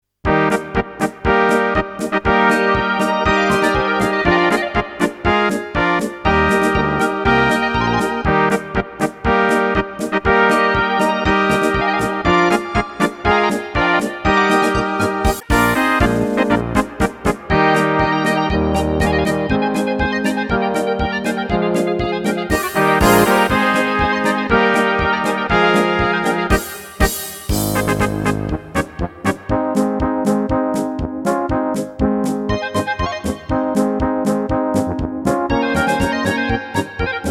Rubrika: Národní, lidové, dechovka
- polka
Karaoke